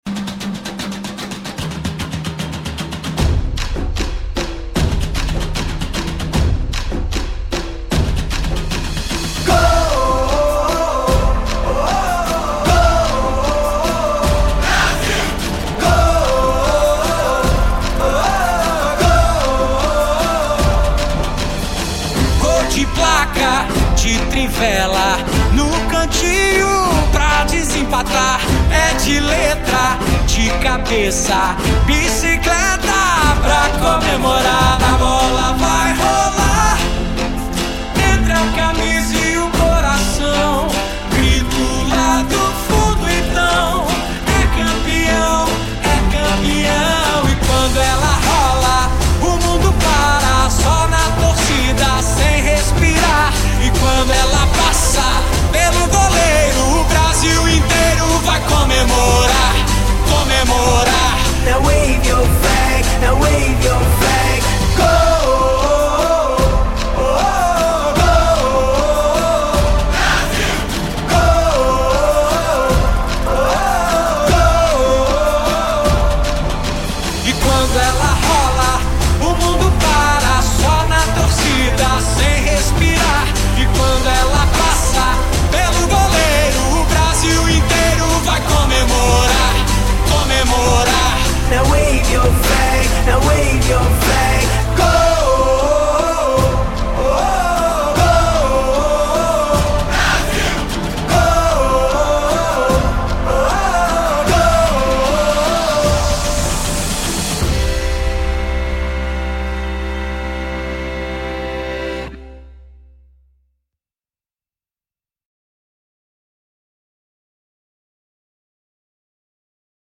BPM152
Audio QualityMusic Cut